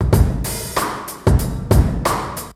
Rim in the Hall 93bpm.wav